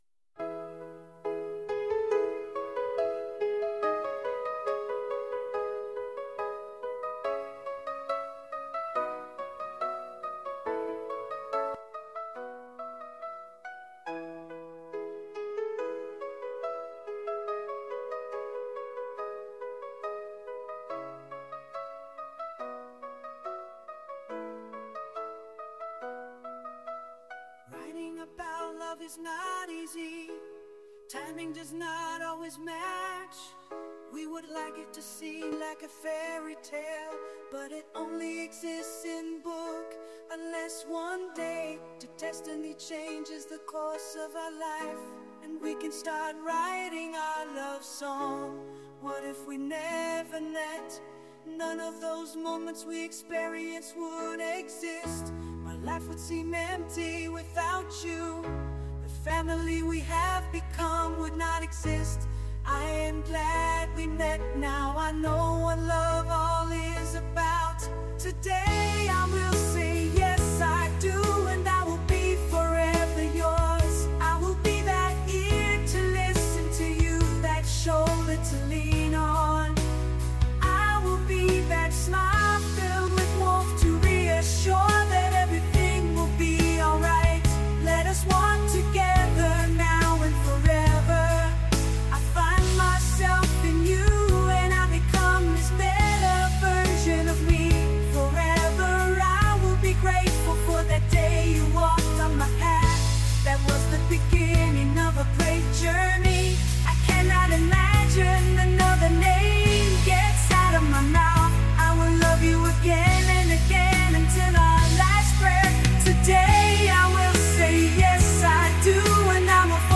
Je me suis amusée à composer les paroles d’une chanson et assisté de l’intelligence artificielle, j’ai ajouté de la musique à mes mots...